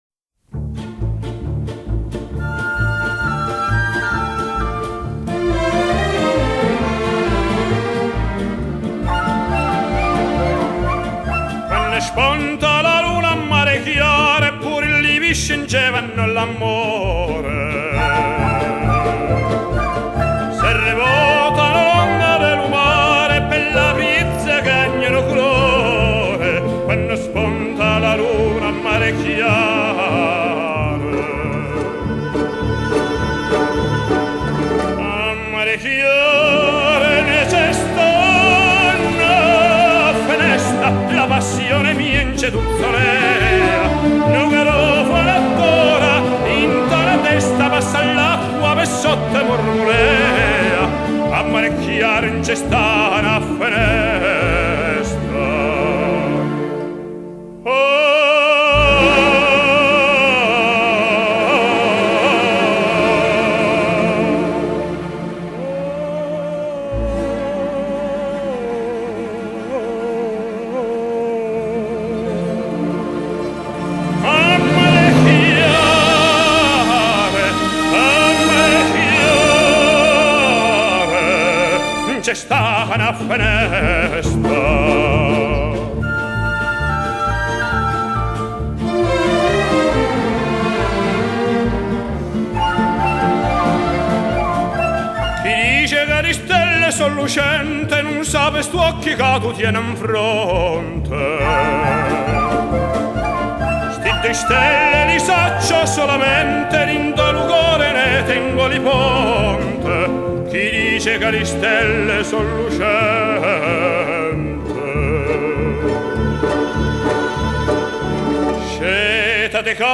【所属类别】音乐 世界音乐